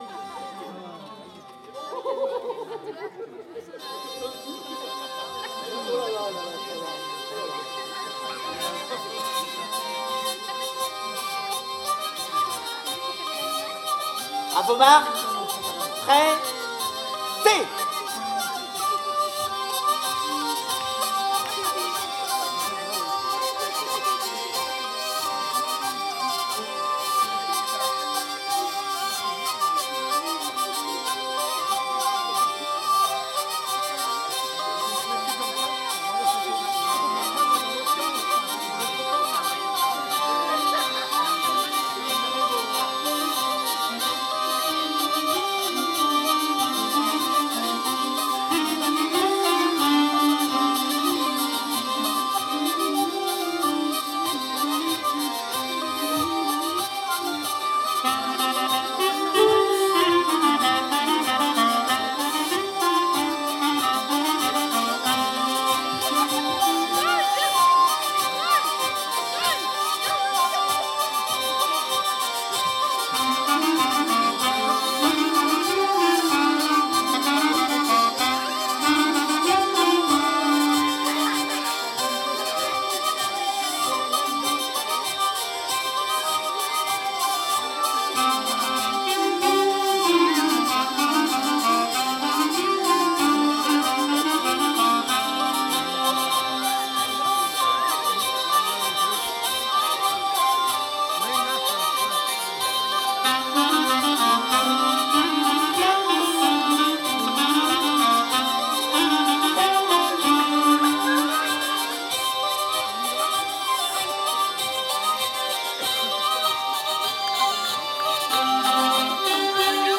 09_hanter_dro-vielle-clarinette.mp3